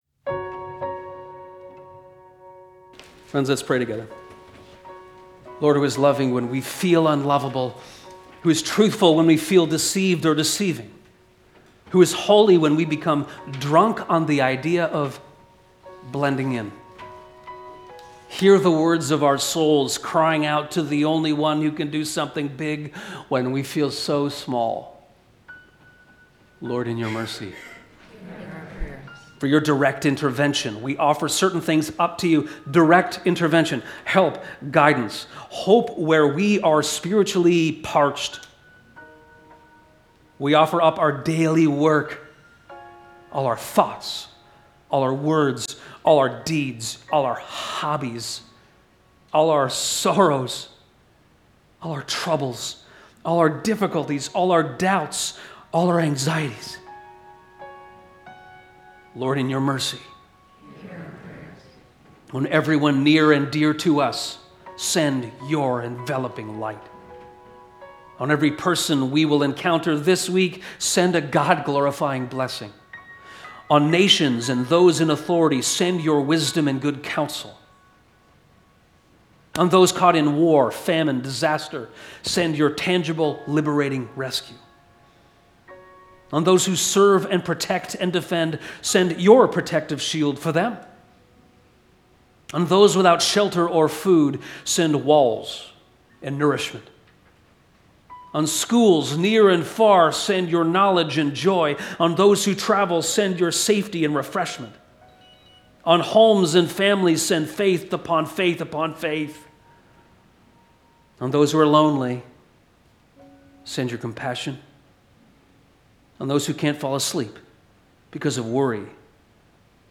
God of hope when we are spiritually parched - a prayer